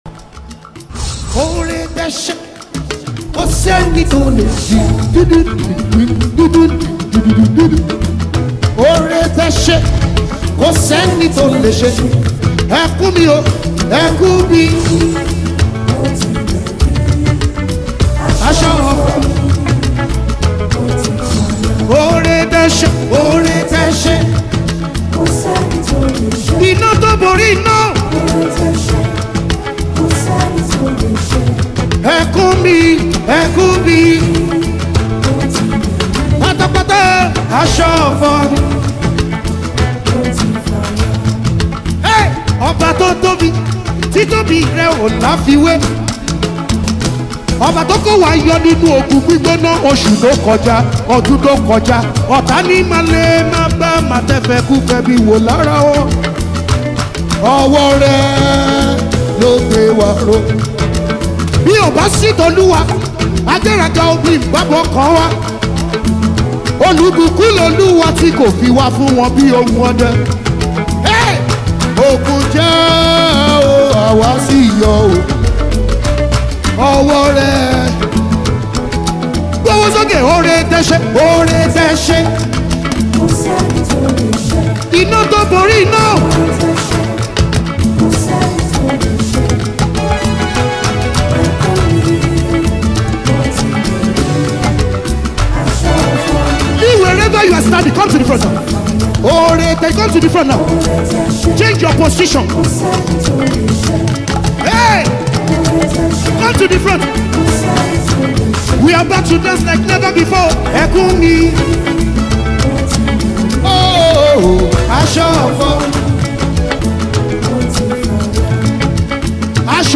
Universal Gospel
The sensational Nigerian gospel musician